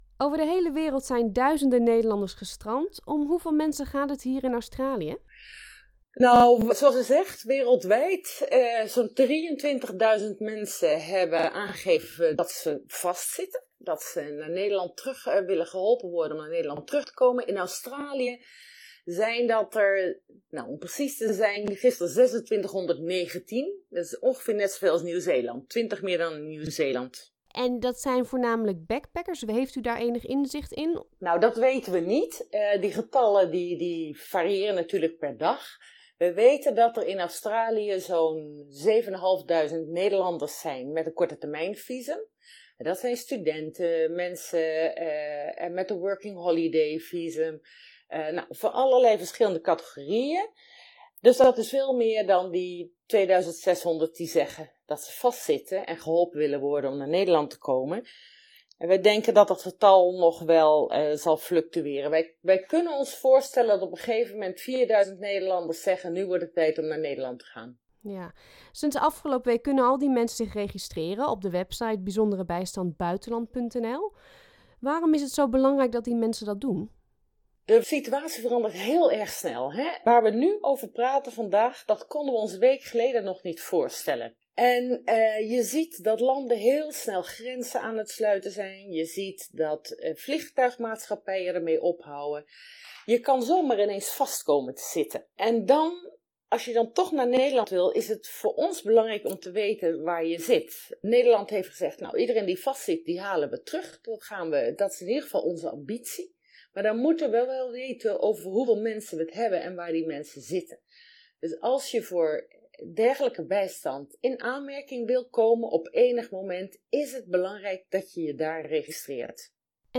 UPDATE: de ontwikkelingen gaan razendsnel, dit interview is niet actueel meer.
interview_ambassadeur_def.mp3